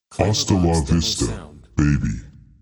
“Hasta la vista, baby” Clamor Sound Effect
Can also be used as a car sound and works as a Tesla LockChime sound for the Boombox.